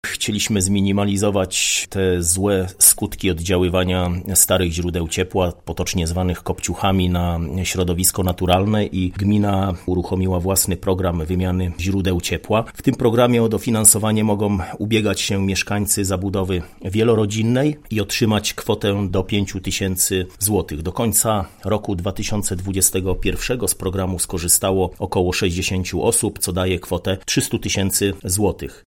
’- Gminny program jest skierowany do osób, które mieszkają w zabudowie wielorodzinnej ponieważ właściciele domów jednorodzinnych mogą korzystać z rządowego programu Czyste powietrze – informuje Mariusz Olejniczak, burmistrz Słubic.